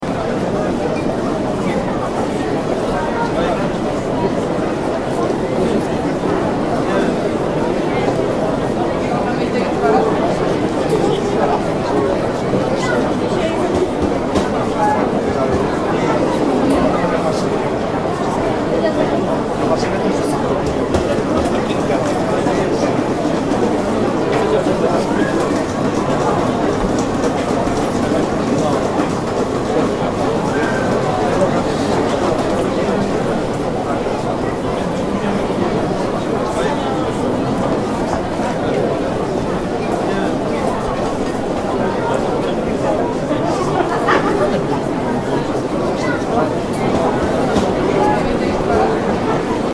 دانلود صدای مرکز خرید از ساعد نیوز با لینک مستقیم و کیفیت بالا
جلوه های صوتی